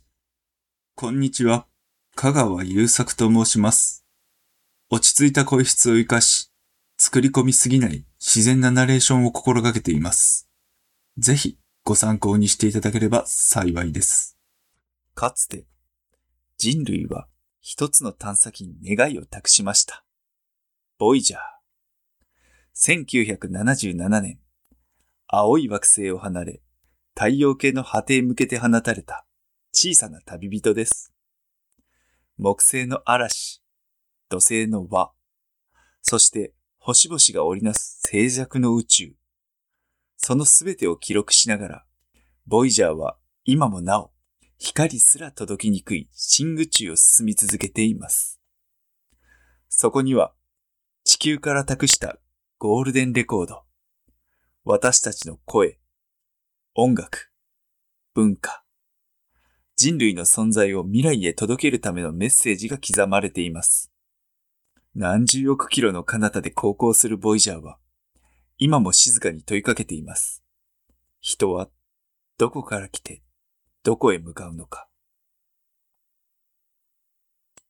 落ち着きと信頼感のある声で、ナレーションを中心に聞き手に安心感を与える表現をお届けします
ボイスサンプル
• 落ち着いた感じで聞き取りやすさを重視したナレーション